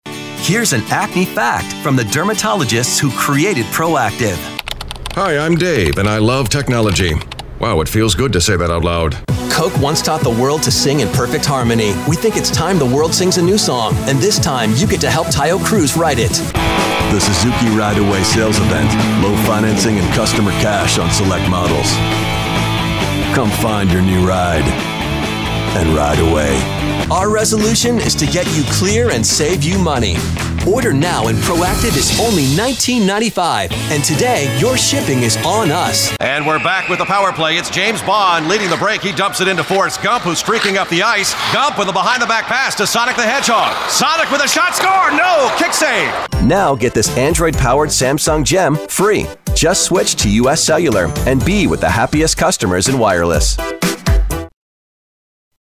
Çeşitli reklamlarda seslendirme yapmıştır.